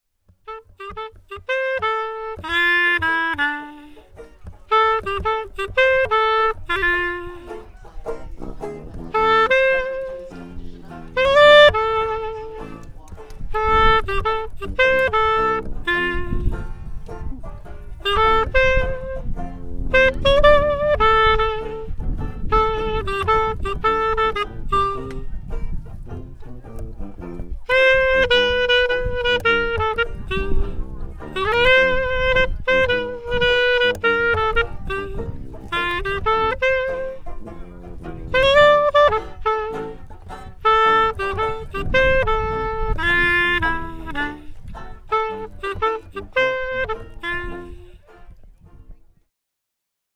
Three microphones (one attached to each instrument), each connected to a portable recorder to go in the musician’s various pockets.
I haven’t yet assembled the separate recordings, but I can confirm that I managed to capture the sound of clarinet, banjo, sousaphone and hat for every single note of our walkaround sets, in crystal clear quality.
Clarinet:
Friday-Set-1-Clari.mp3